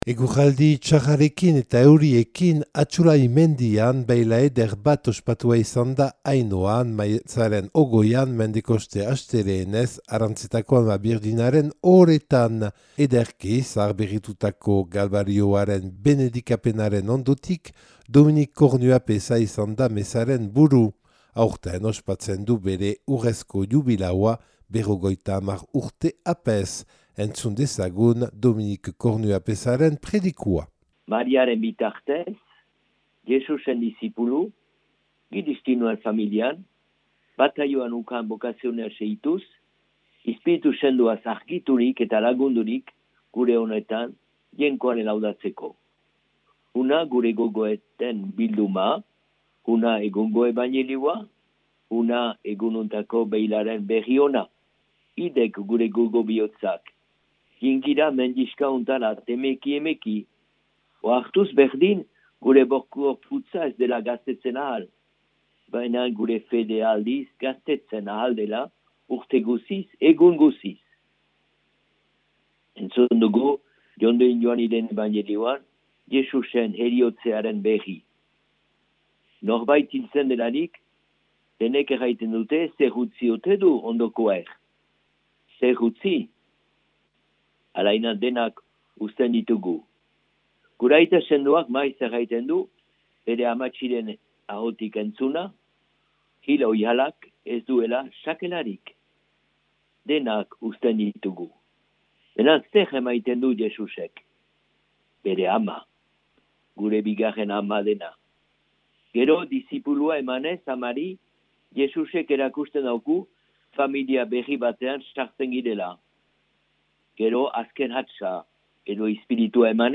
Eguraldi txarrarekin eta euriekin, Atsulai mendian beila eder bat ospatua izan da Ainhoan maiatzaren 20an Mendekoste astelehenez Arantzetako Ama Birjinaren ohoretan.